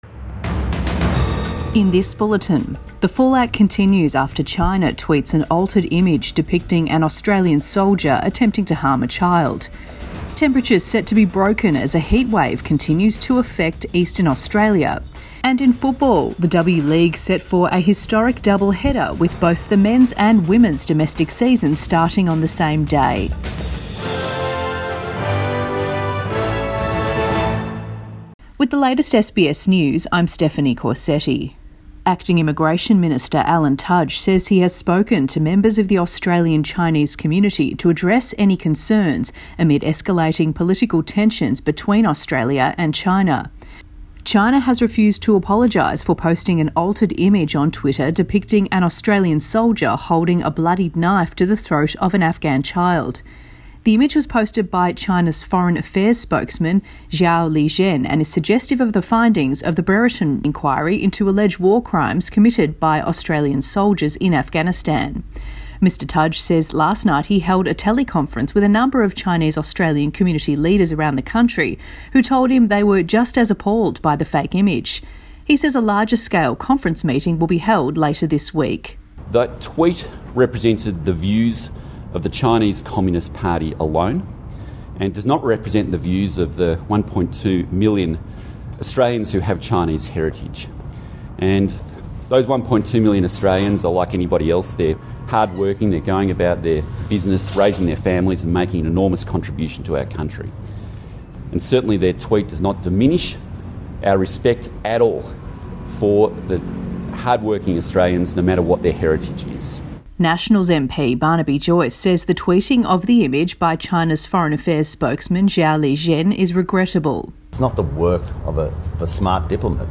Midday bulletin 1 December 2020